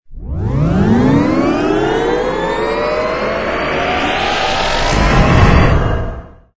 engine_rh_cruise_start.wav